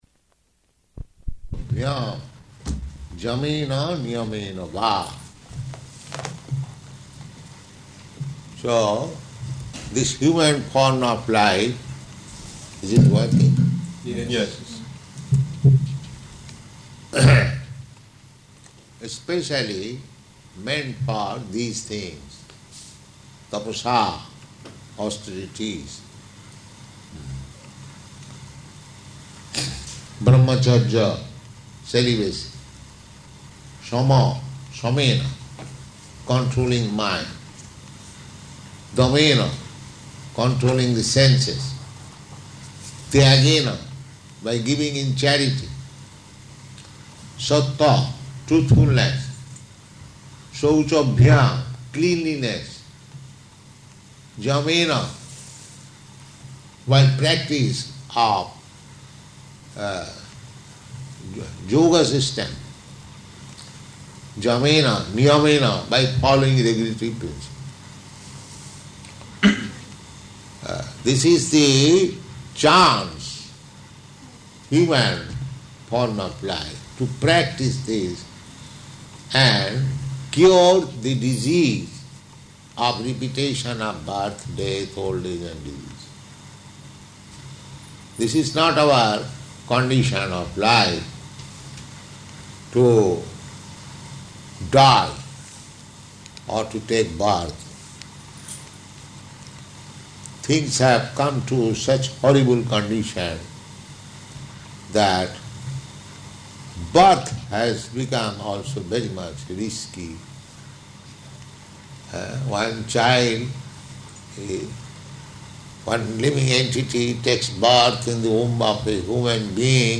Śrīmad-Bhāgavatam 6.1.13–15 --:-- --:-- Type: Srimad-Bhagavatam Dated: February 21st 1973 Location: Auckland Audio file: 730221SB.AUC.mp3 Prabhupāda: yam... yamena niyamena vā [SB 6.1.13] So, this human form of life...